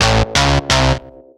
Arbeit Nervt_Instrumental short.wav